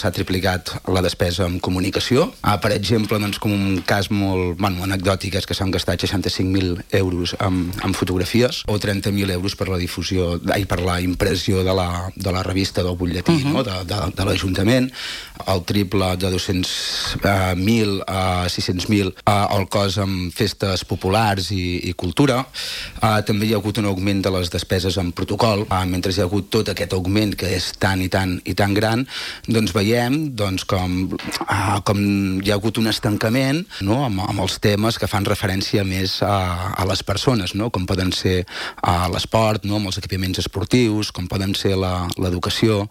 Moment de l'entrevista d'ahir